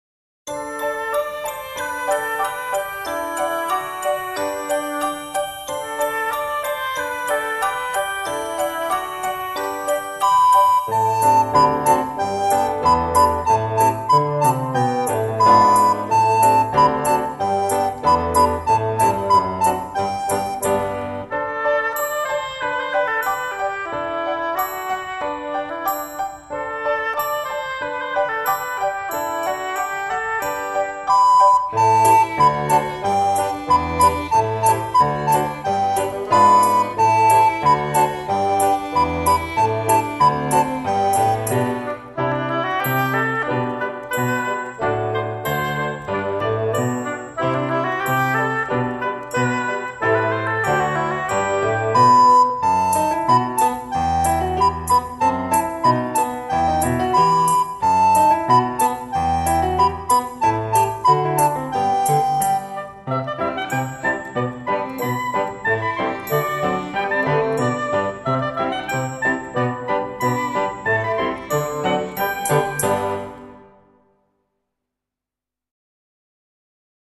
Campanitas sonarán (rápida)